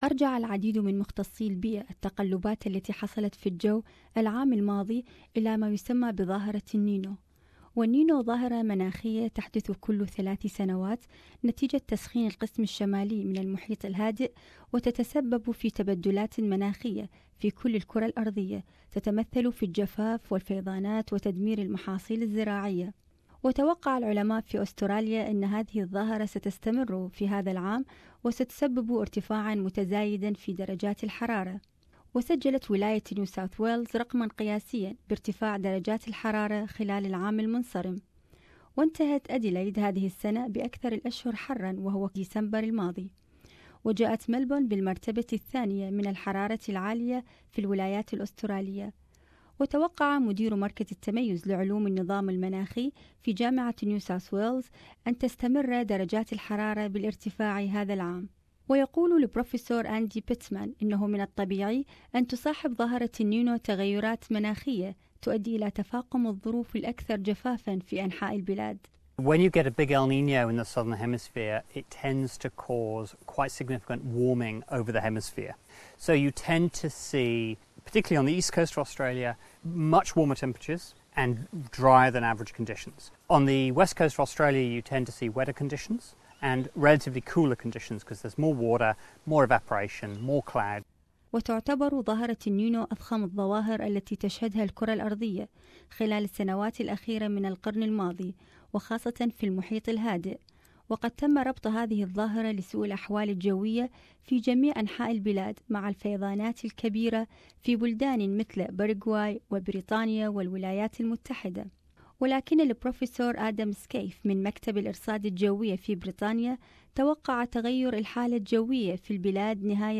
التقرير